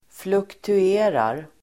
Ladda ner uttalet
Uttal: [fluktu'e:rar]